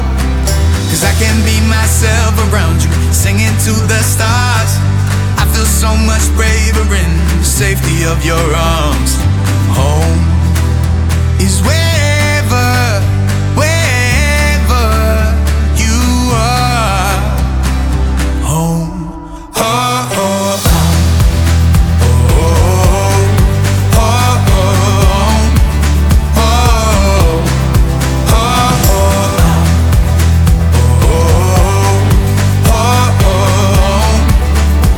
2025-01-17 Жанр: Кантри Длительность